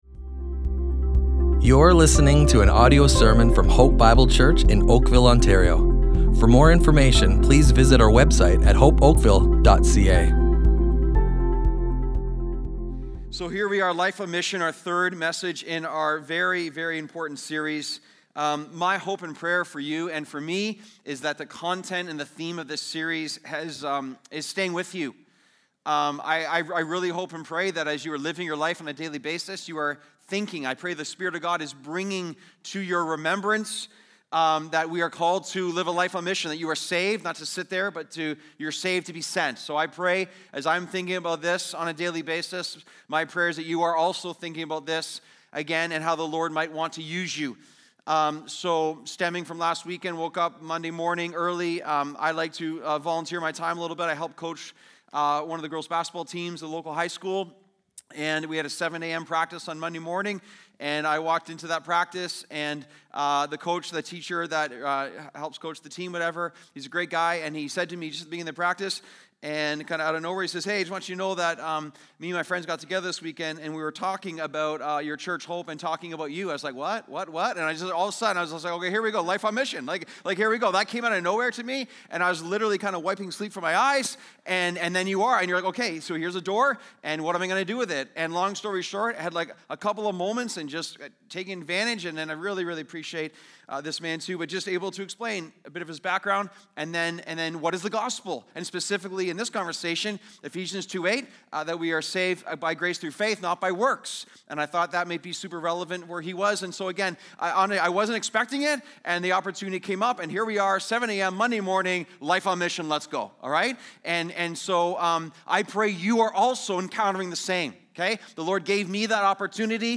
Hope Bible Church Oakville Audio Sermons Life on Mission // Lessons from the Early Church!